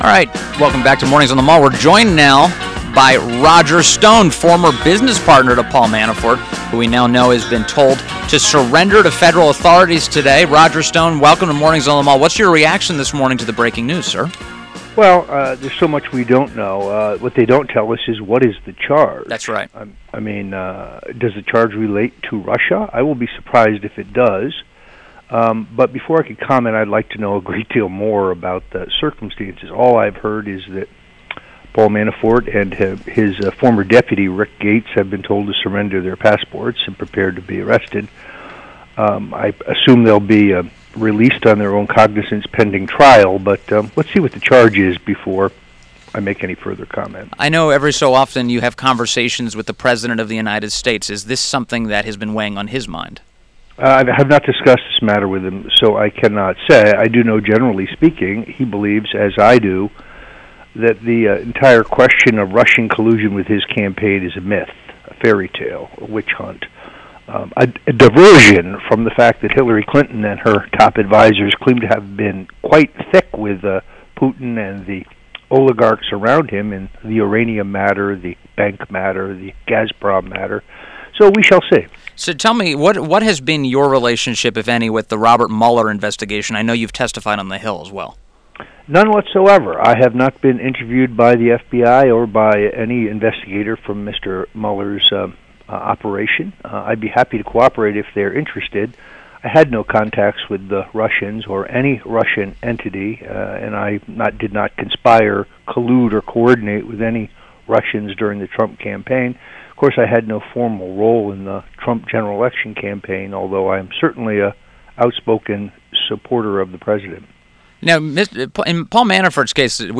WMAL Interview - ROGER STONE - 10.30.17